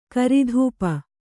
♪ karidhūpa